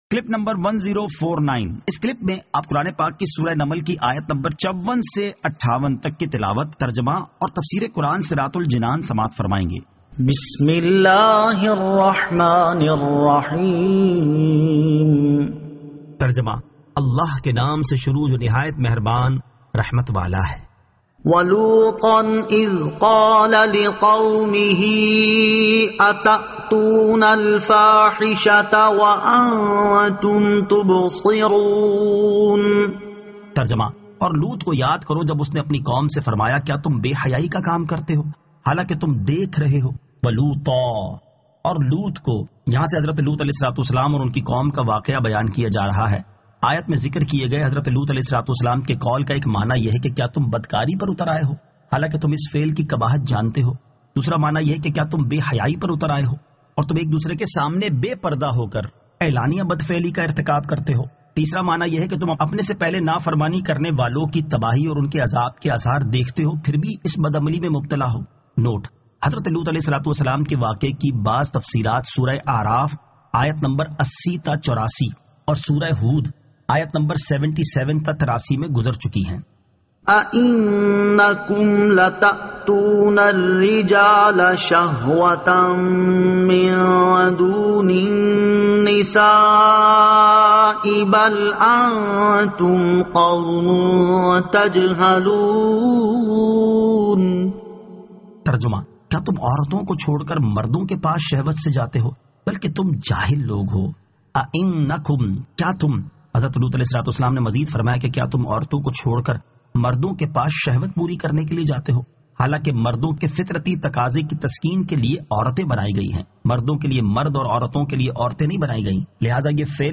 Surah An-Naml 54 To 58 Tilawat , Tarjama , Tafseer